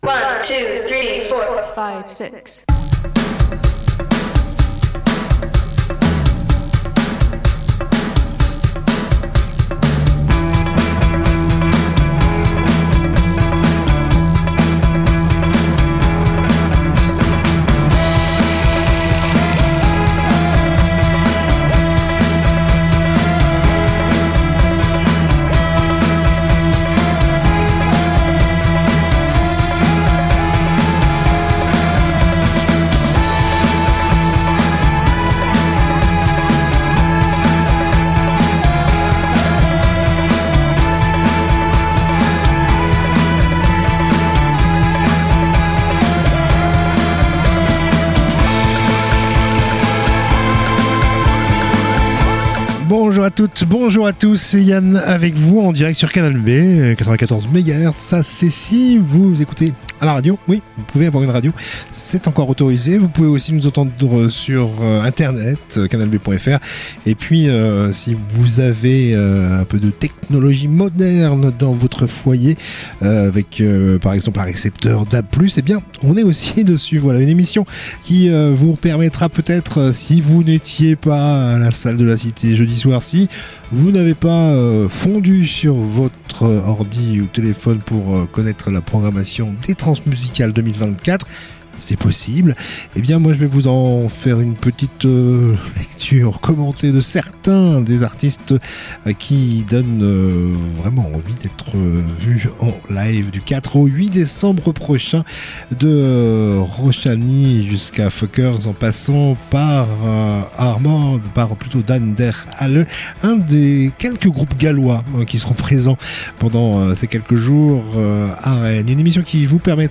itv culture